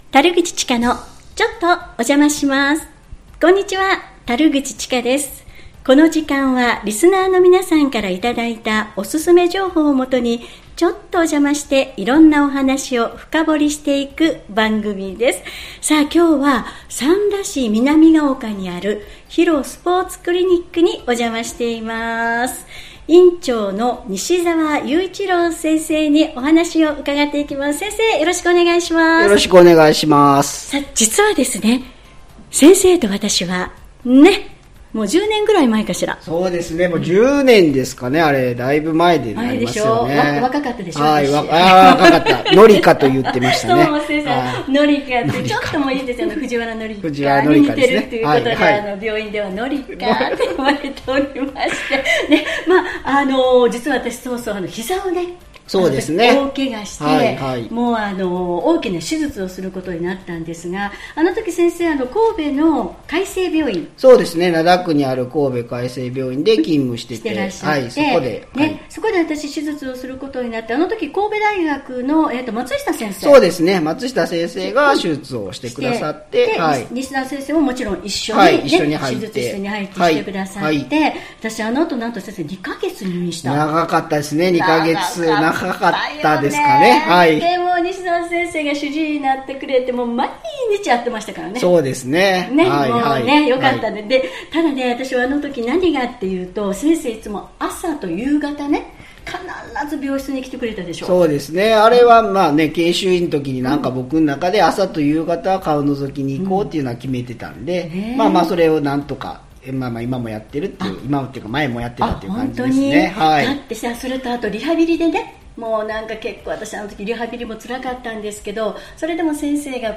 マイク片手に気になるスポットを訪ねていろいろインタビューする番組